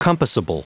Prononciation du mot compassable en anglais (fichier audio)
Prononciation du mot : compassable